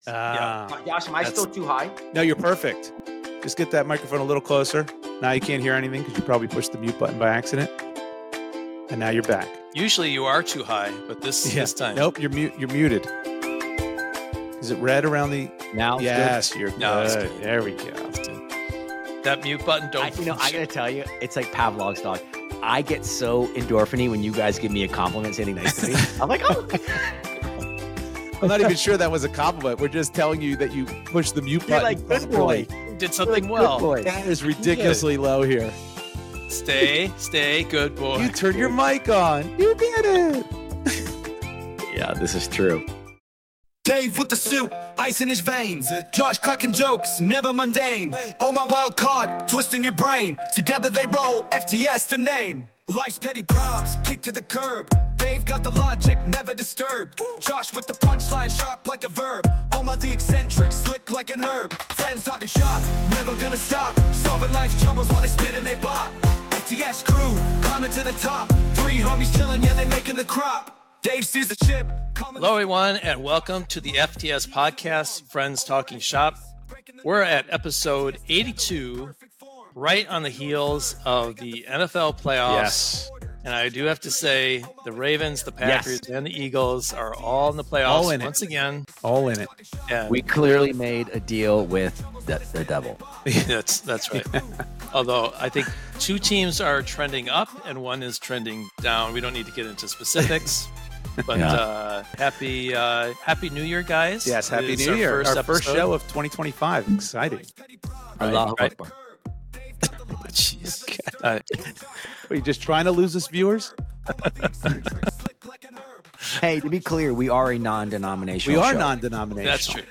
long time friends who get together, for your listening pleasure, to vigorously debate and solve life's most insignificant, yet irritating, problems in an unrehearsed and unscripted format where anything goes and feelings don't matter.